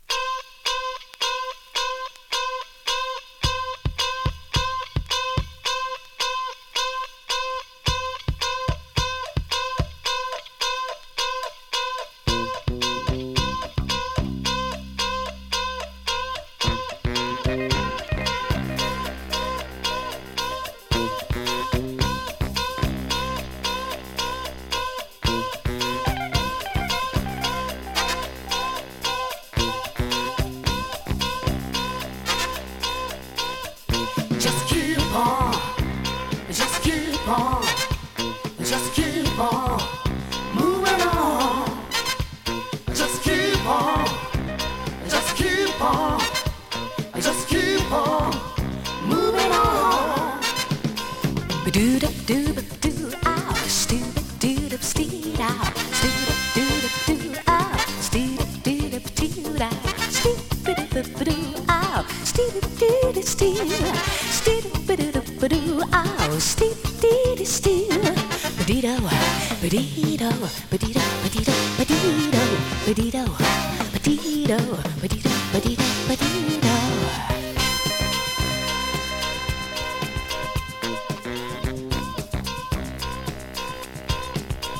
スキャットもクールなファンキー・ヴォーカル・ジャズ・ファンク曲！